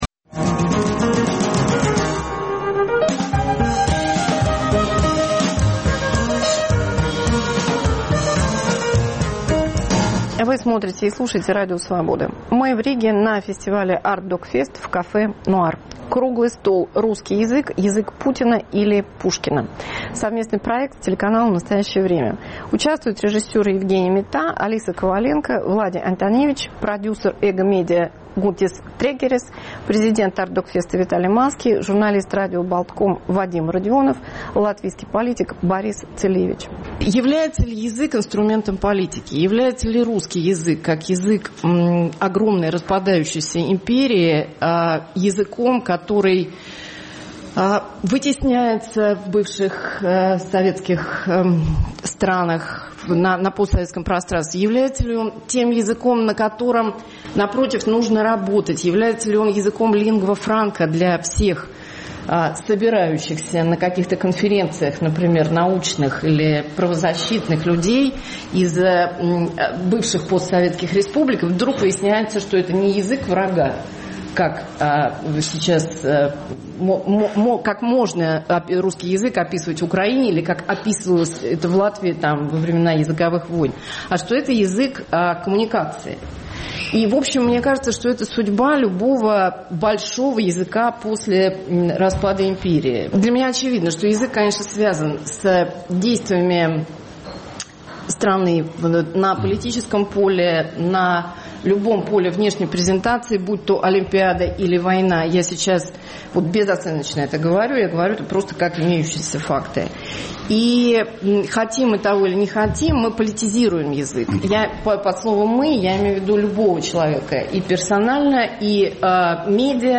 Дискуссия на фестивале “Артдокфест” в Риге. Русский язык как постимперская проблема.
Спорят режиссеры, политики и журналисты.